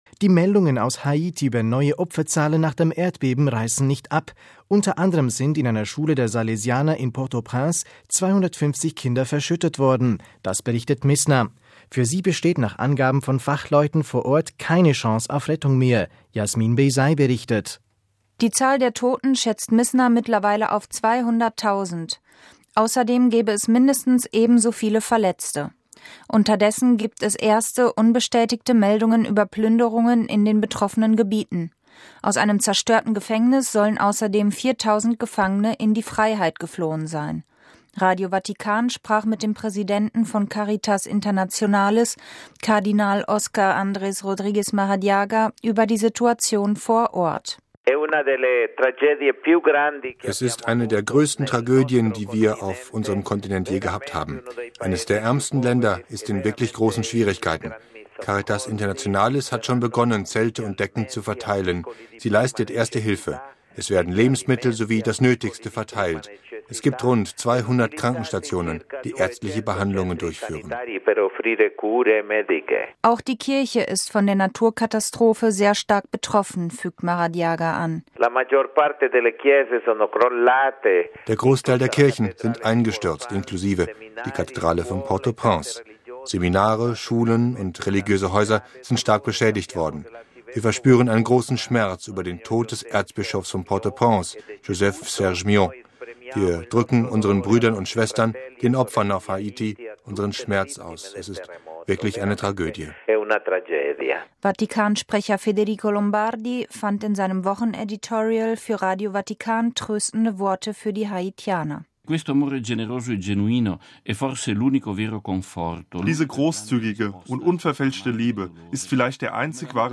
Radio Vatikan sprach mit dem Präsidenten von Caritas Internationalis, Kardinal Òscar Andrés Rodríguez Maradiaga, über die Situation vor Ort: